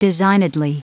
Prononciation du mot designedly en anglais (fichier audio)
Prononciation du mot : designedly